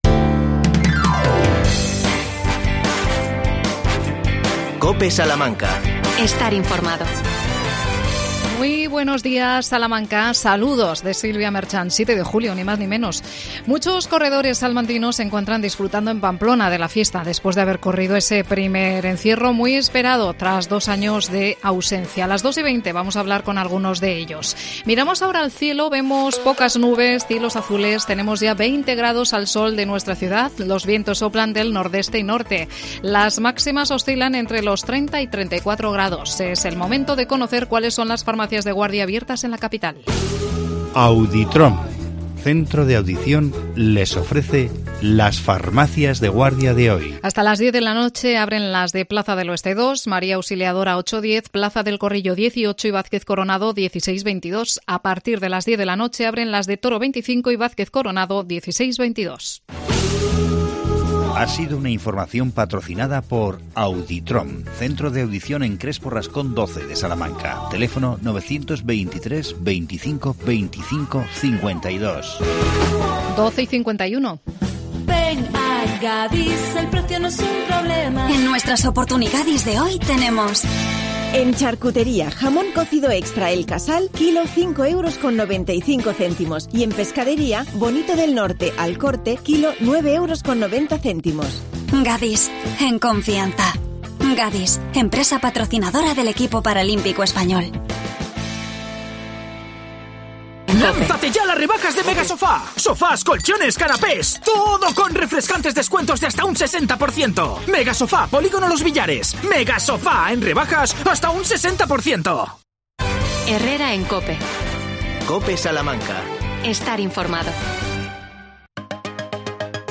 AUDIO: Agricultores contra el Cambio Climático. Entrevista